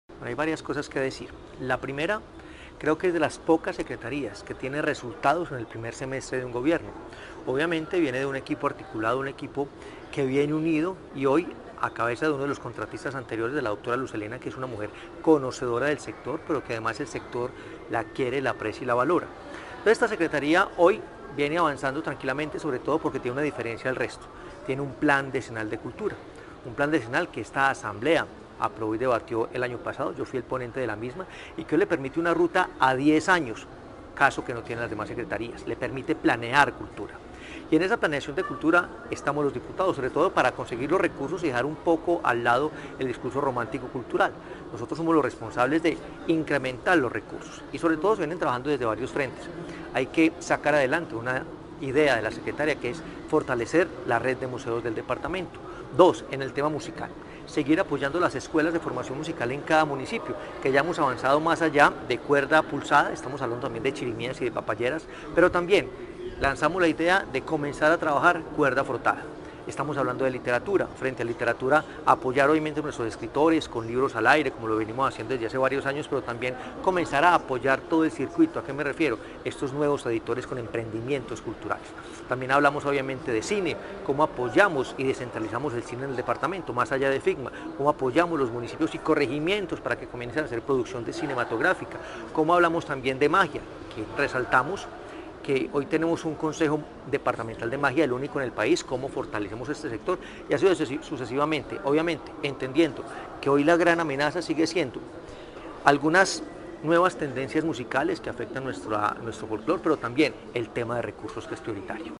Diputado de Caldas, Hernán Alberto Bedoya.